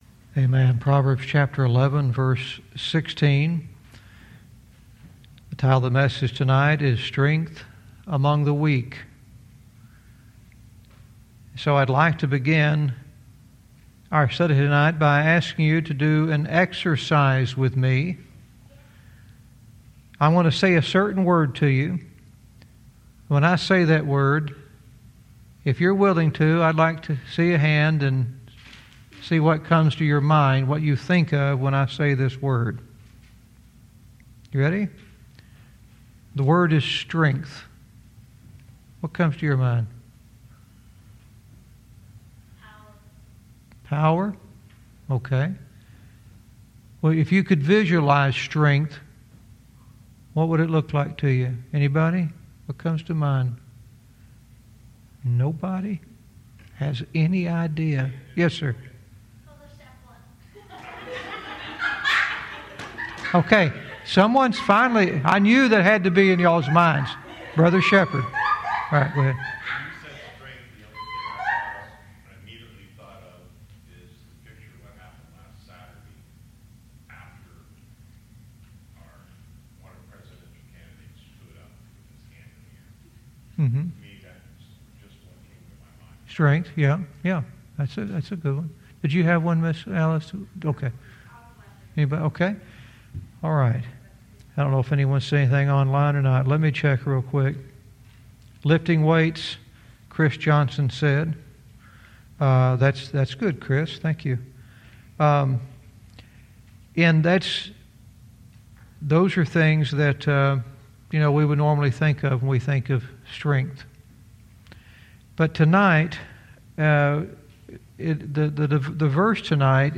Verse by verse teaching - Proverbs 11:16 "Strength Among the Weak"